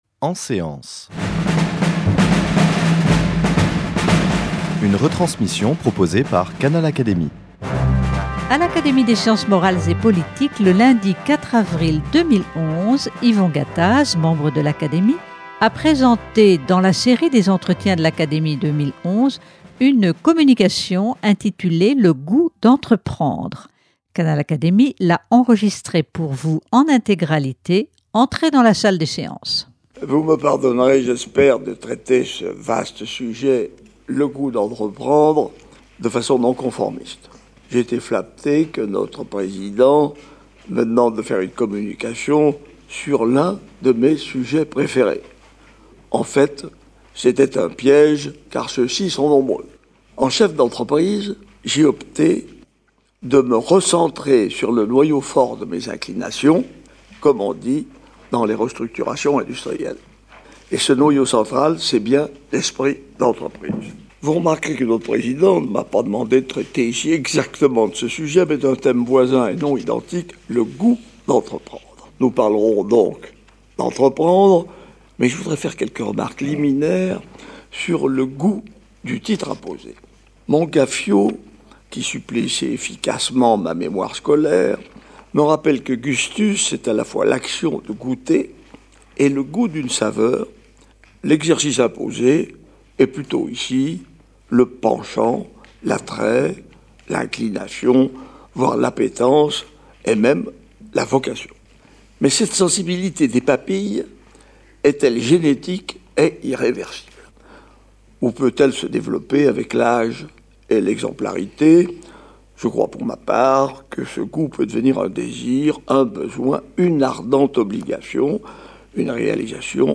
Cette communication d'Yvon Gattaz a été présentée le lundi 4 avril 2011devant l’Académie des sciences morales et politiques.